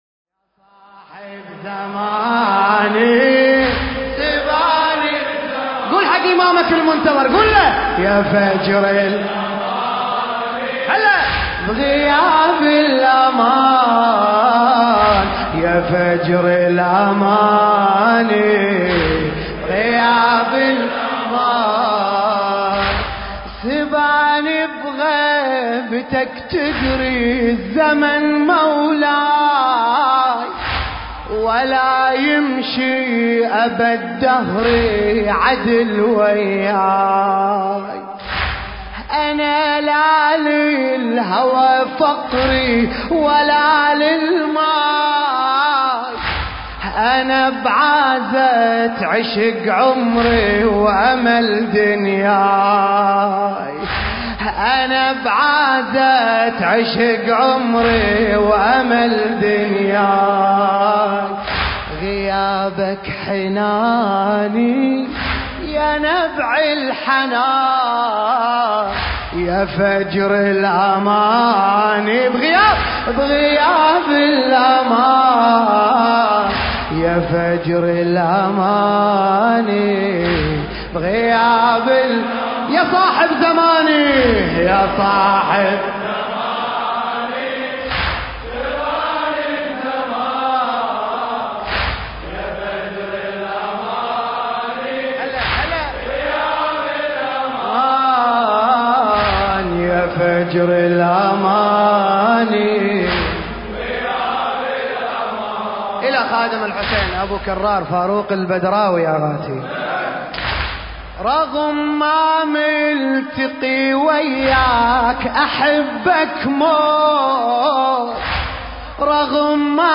المكان: حسينية آل ياسين – الكويت